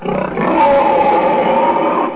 咆哮
描述：我为一个短视频的场景生成了这个声音，其中一个僵尸攻击我的朋友。 这是用Audacity改变的一阵响亮的吼叫声和咆哮声。 我录制了自己最好的僵尸声音，然后我降低了音调，提高了低音，并添加了一个相位器，使其更加湿润，并添加了一些反馈。
标签： 战斗 哭泣 武士 ORC 僵尸 轰鸣 缠结 攻击
声道立体声